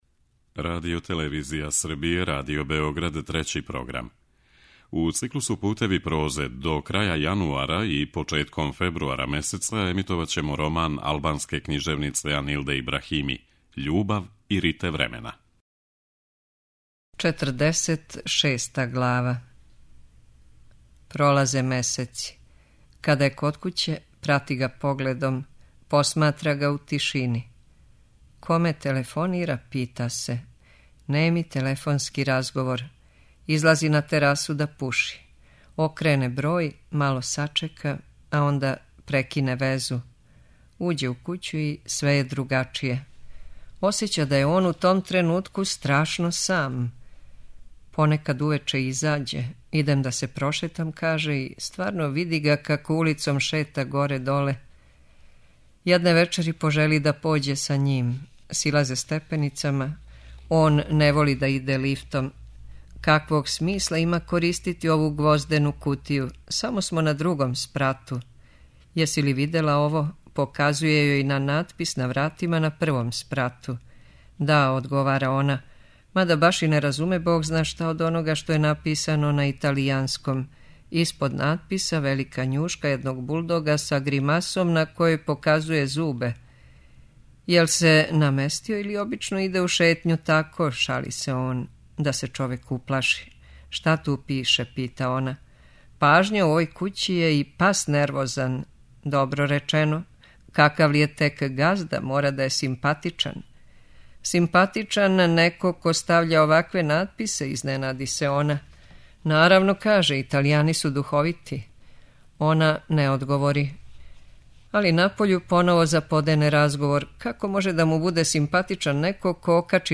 Књига за слушање: Анилда Ибрахими: Љубав и рите времена (25)